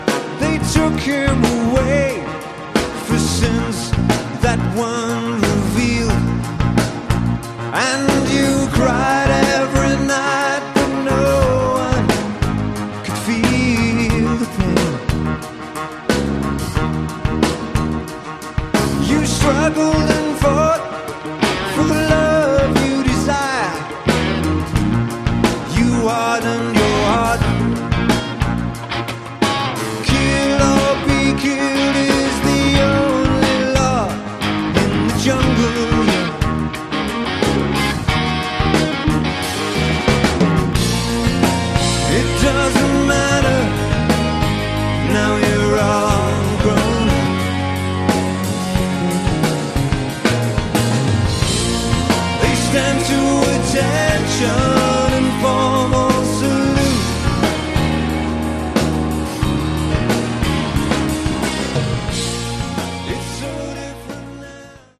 Category: AOR
lead vocals, keyboards
guitars
bass, vocals
drums, vocals